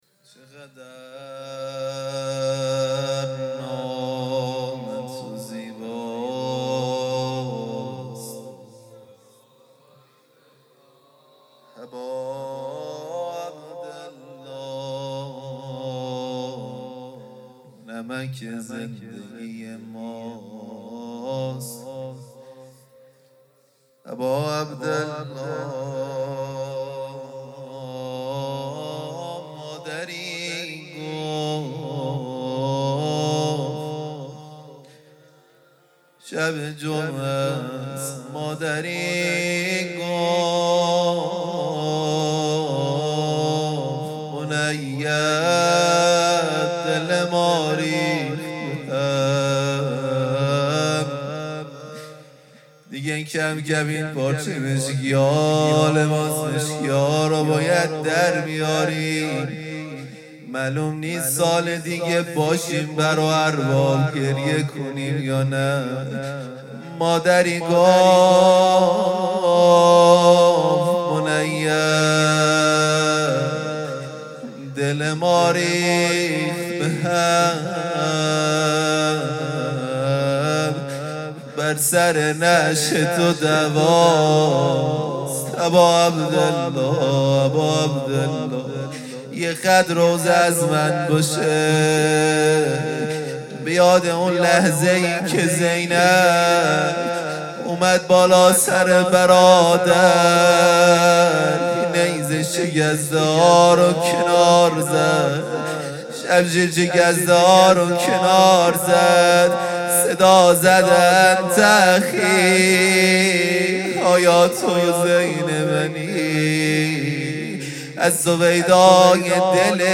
مناجات پایانی | چقدر نام تو زیباست اباعبدلله| ۱۵ مهر ماه ۱۴۰۰
جلسه‌ هفتگی | شهادت امام رضا(ع) | ۱۵ مهر ماه ۱۴۰۰